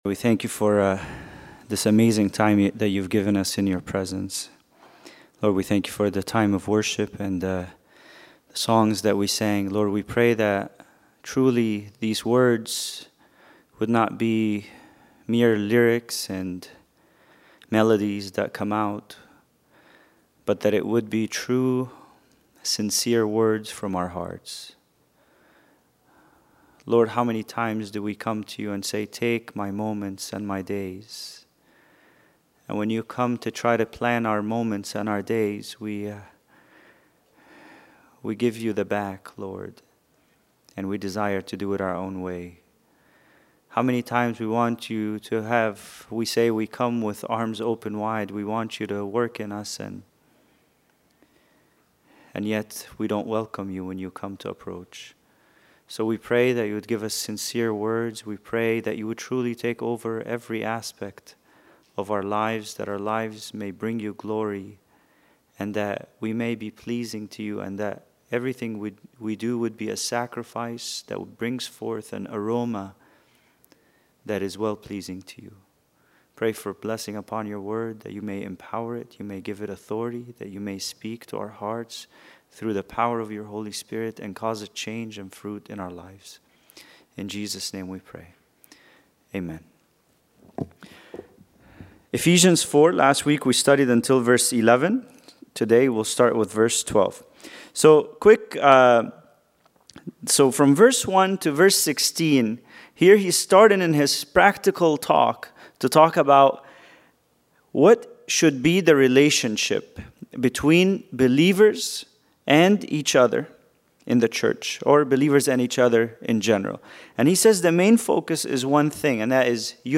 Bible Study: Ephesians 4:12-14